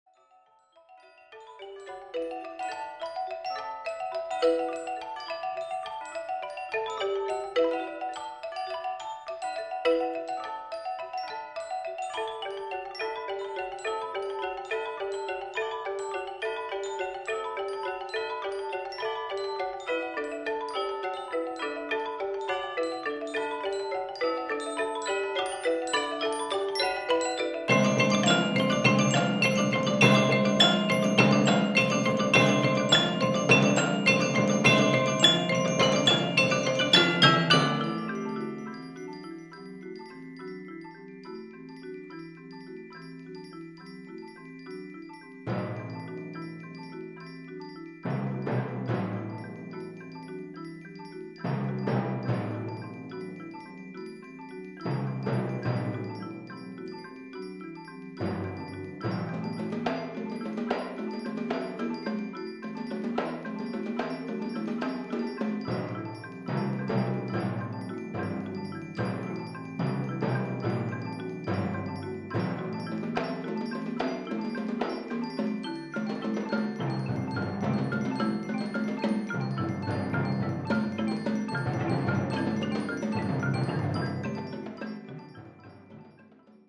for Percussion Ensemble